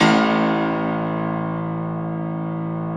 53a-pno01-A-1.aif